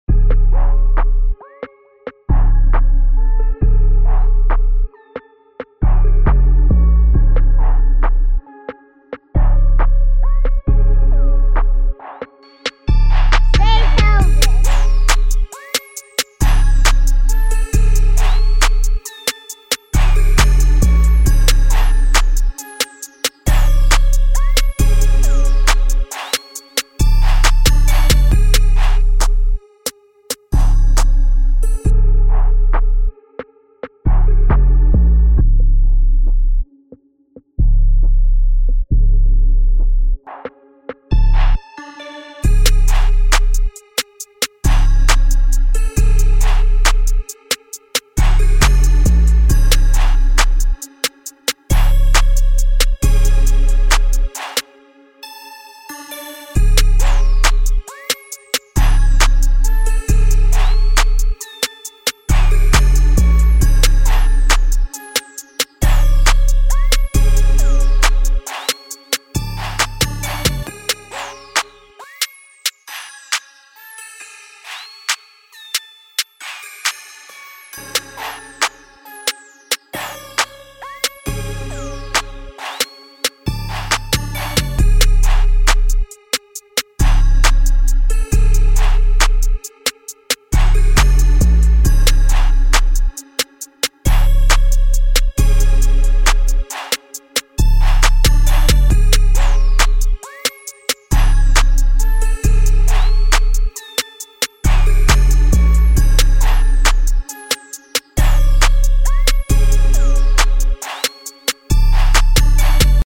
Trap Instrumental